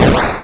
bakuhatu103.wav